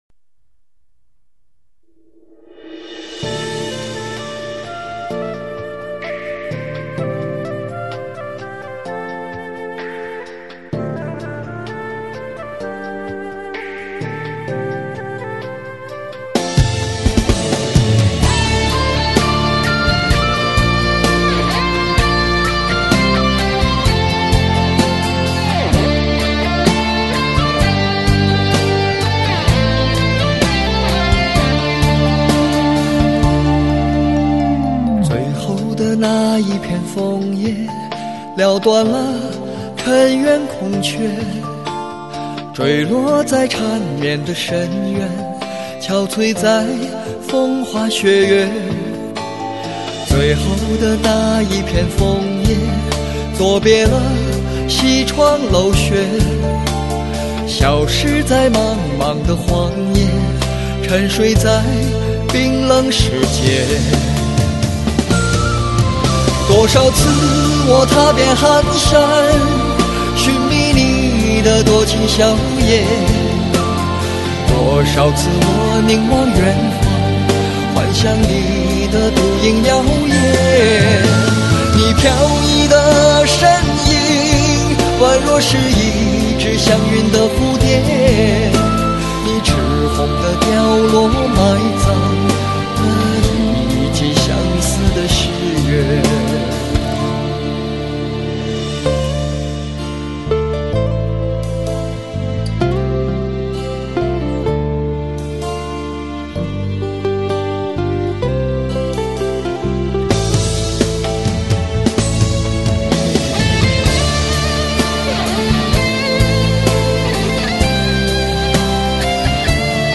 原汁原味的人声佳音，绝不媚俗的感性旋律，委婉动听